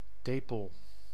Ääntäminen
France: IPA: [mam.lɔ̃]